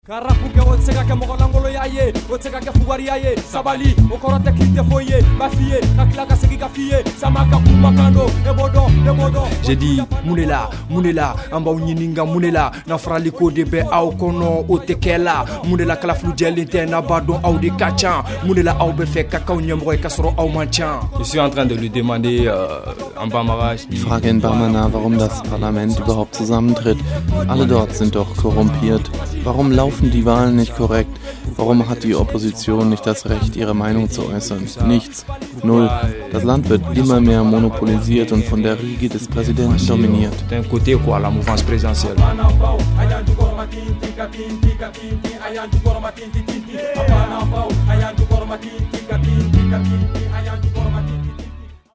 Die Rapper der Band Tatapound bei Radio Kledu